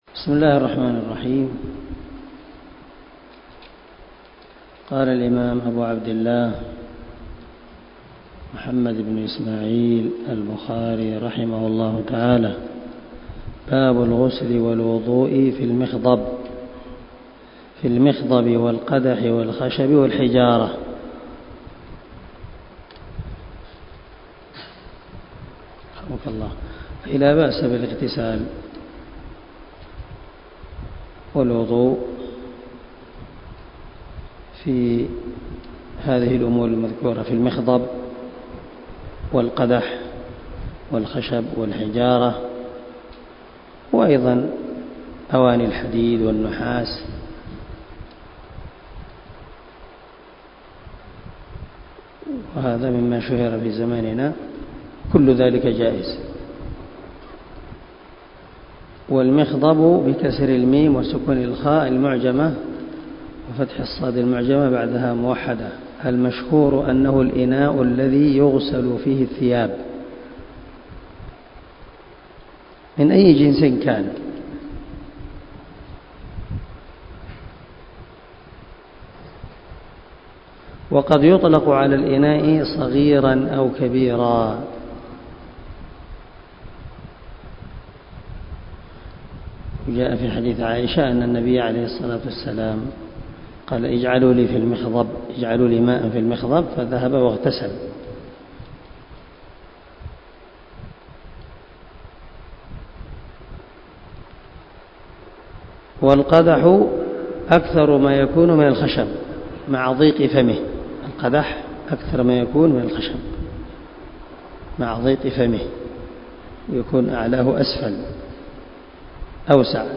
171الدرس 47 من شرح كتاب الوضوء حديث رقم ( 195 - 197 ) من صحيح البخاري